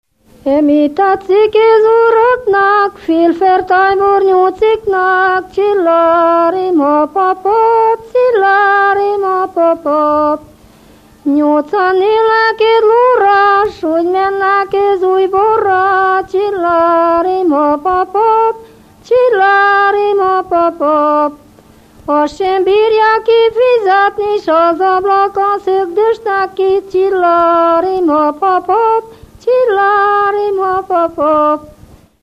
Moldva és Bukovina - Moldva - Klézse
ének
Stílus: 8. Újszerű kisambitusú dallamok
Szótagszám: 7.7.6.4
Kadencia: 4 (3) 4 1